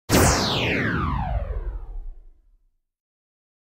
laser
laser-sound-effect.mp3